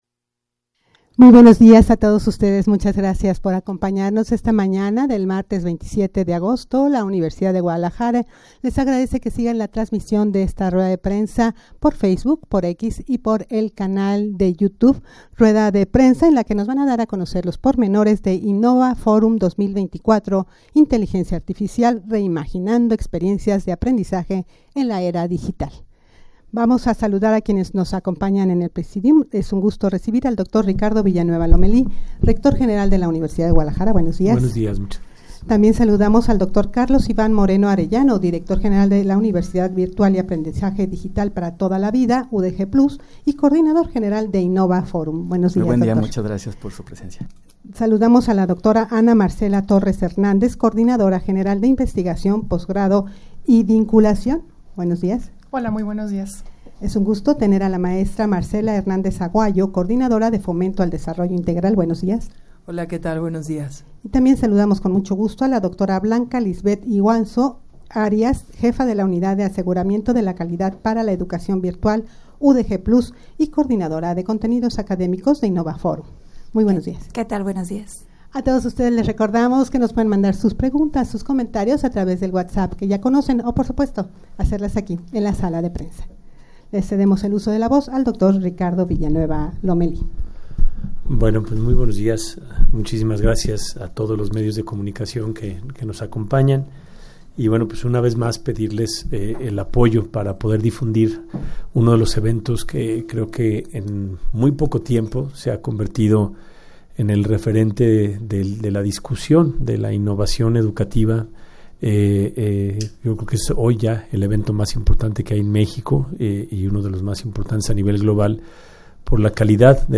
Audio de la Rueda de Prensa
rueda-de-prensa-para-dar-a-conocer-los-pormenores-de-innova-forum-2024-ia.mp3